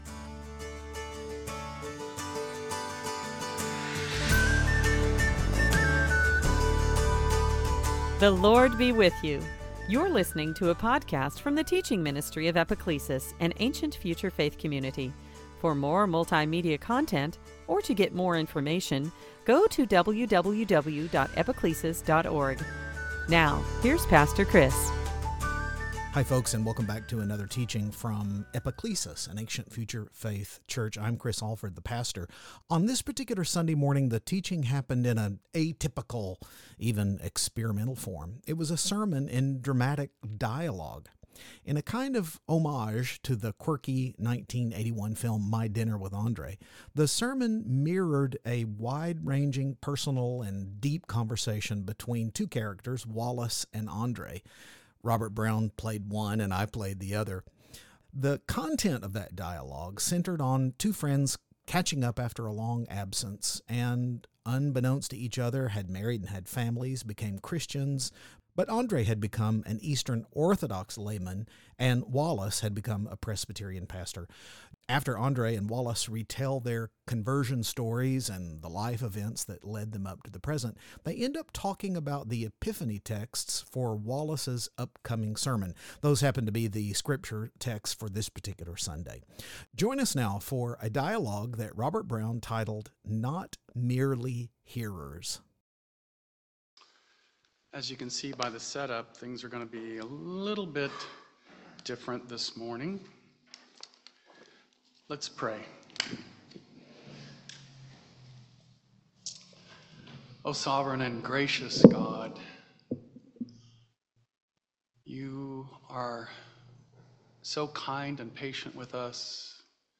This Sunday morning teaching happened in an atypical, even experimental form— a sermon in dramatic dialogue.